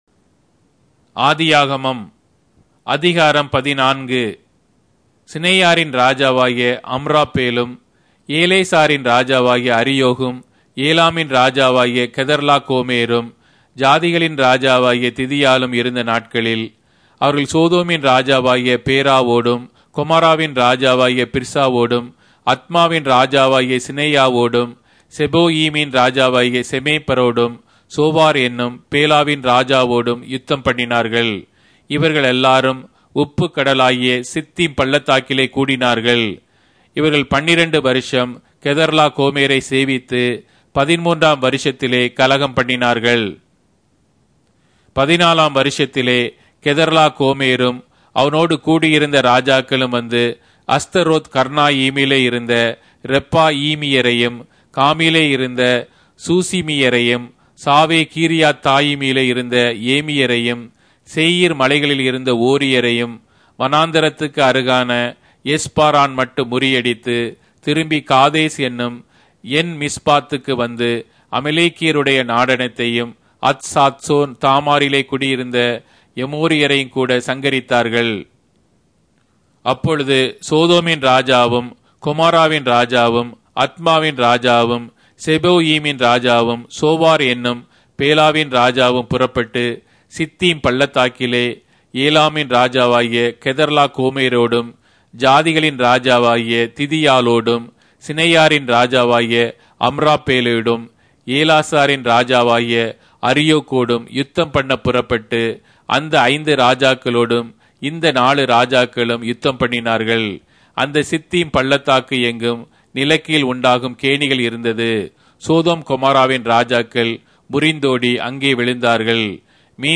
Tamil Audio Bible - Genesis 16 in Mrv bible version